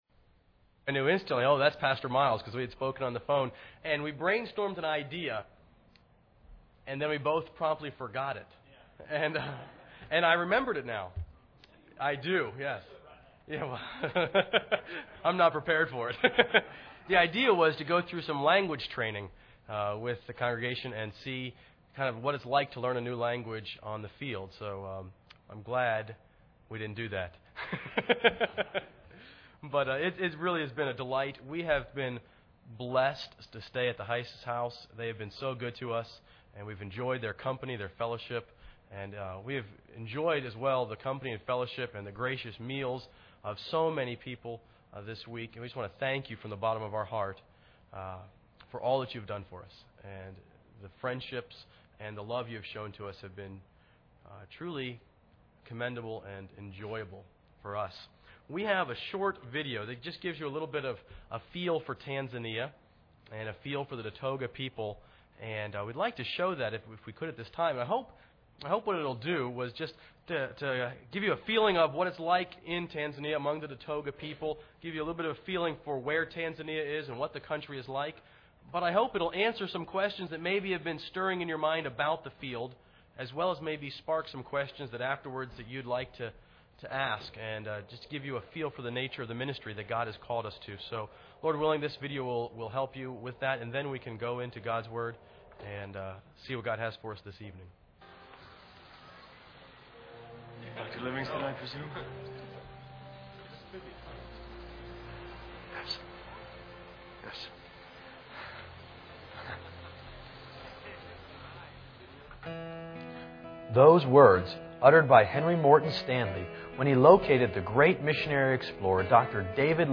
Series: 2010 Missions Conference Service Type: Special Service